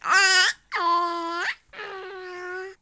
waves.ogg